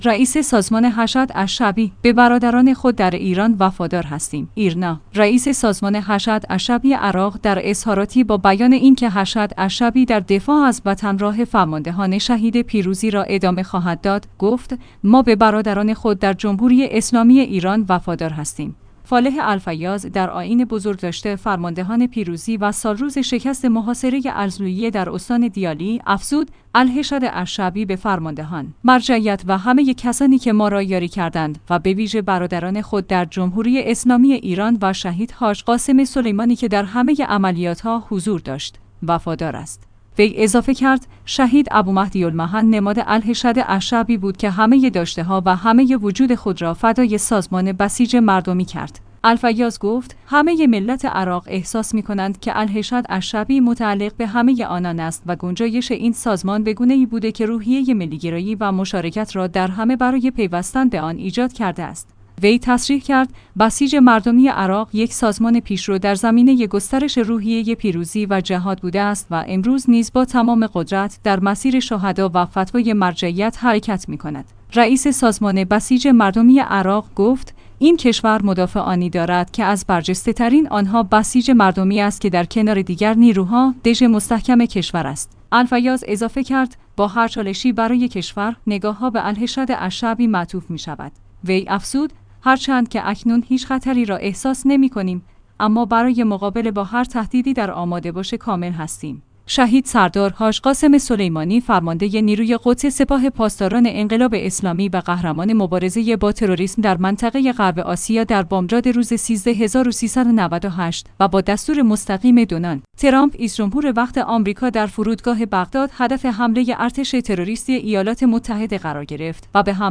فالح الفیاض در آئین بزرگداشت فرماندهان پیروزی و سالروز شکست محاصره الضلوعیه در استان دیالی، افزود: الحشد